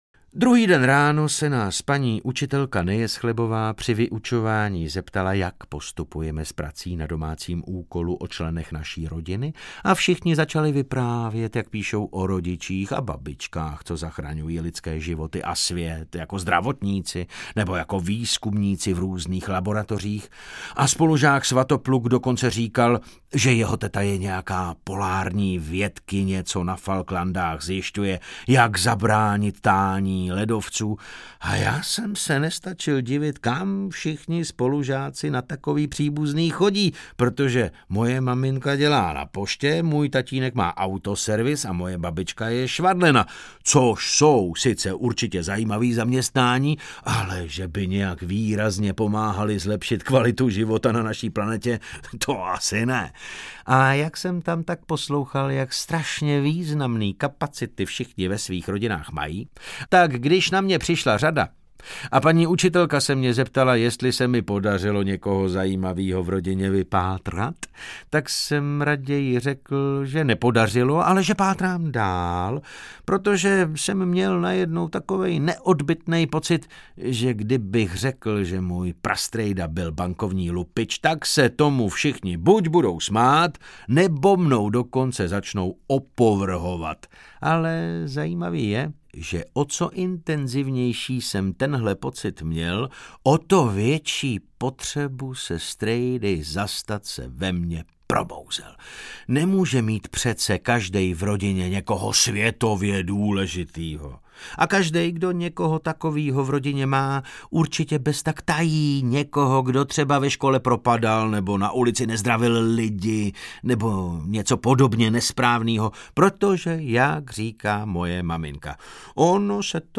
Ruce vzhůru, tohle je přepadení a nikdo ani hnout! audiokniha
Ukázka z knihy
Natočeno ve Studiu DAMU.